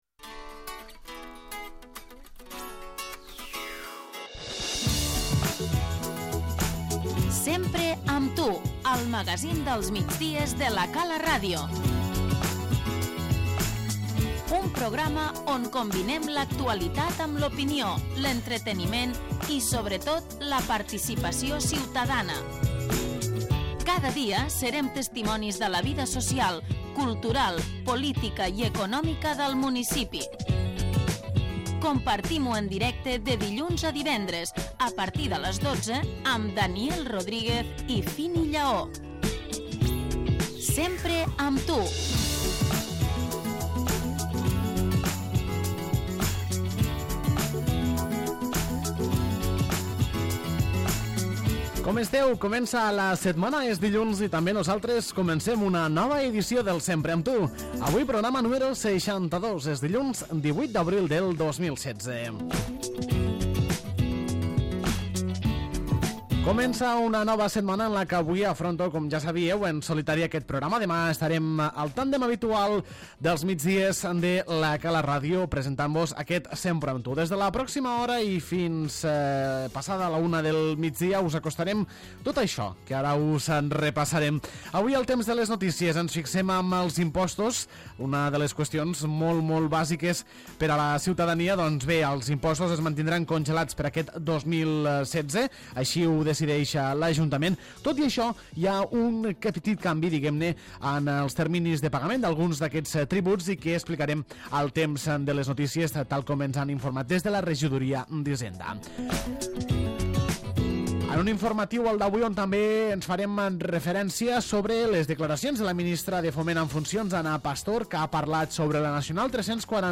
Benvingudes i benvinguts una setmana més al magazín dels migdies de La Cala RTV.